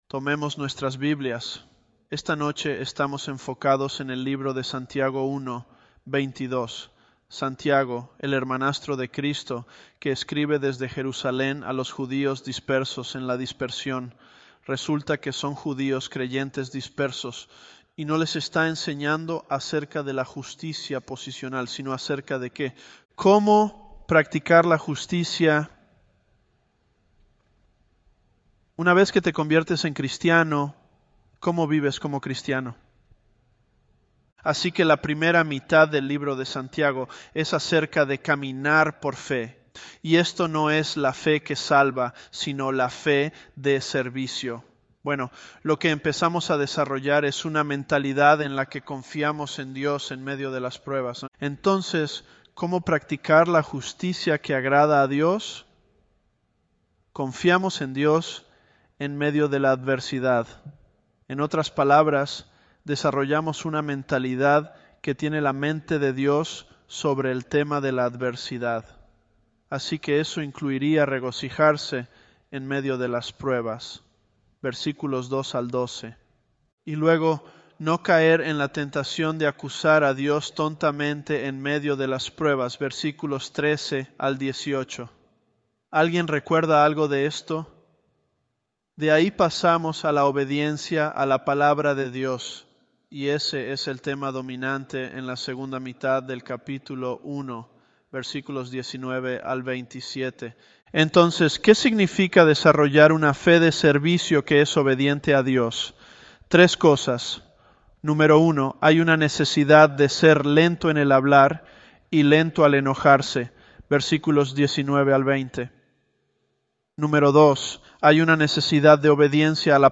Elevenlabs_James007.mp3